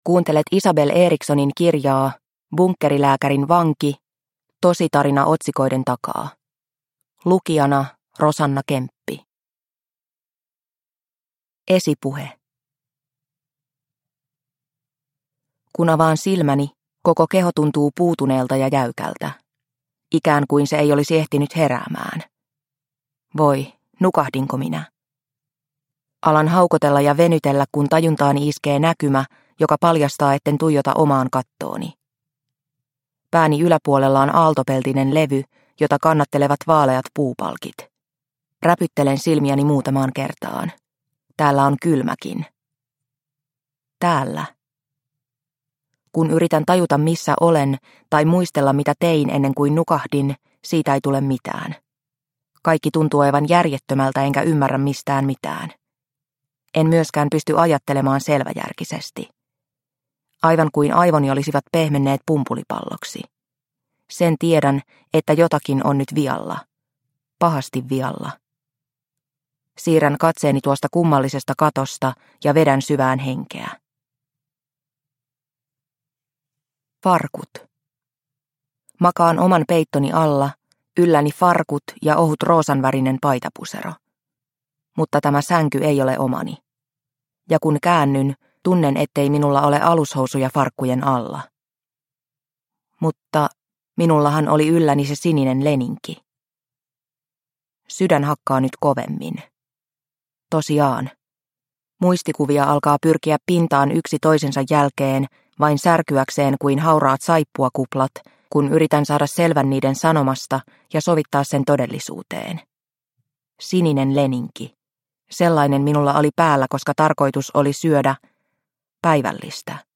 Bunkkerilääkärin vanki (ljudbok) av Isabel Eriksson